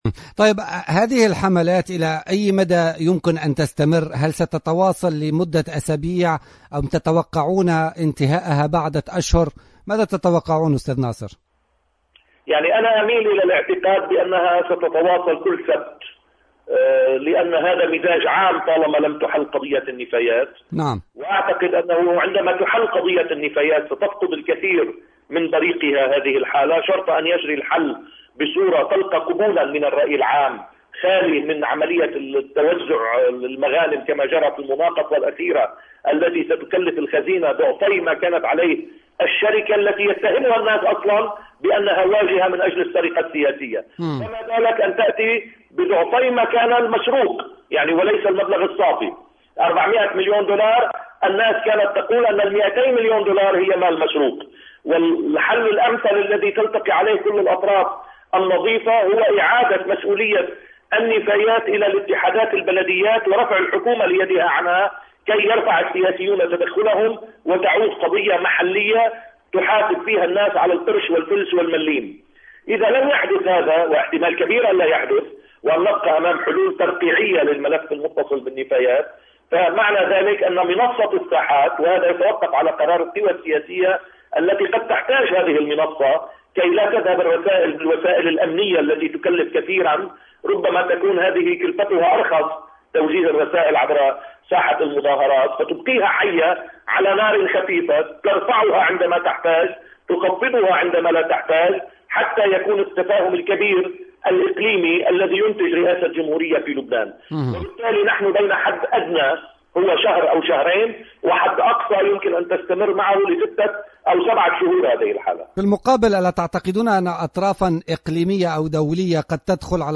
أكد الاعلامي اللبناني ناصر قنديل في تصريح لجوهرة أف أم في برنامج بوليتكا اليوم الجمعة 28 أوت 2015 أن الاحتجاجات والحملات التي انطلقت في لبنان ستتواصل كل يوم سبت ما لم تحل أزمة النفايات ولكنها في اعتقاده ستفقد الكثير من بريقها بعد حل هذه المشكلة.